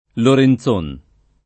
[ loren Z1 n ]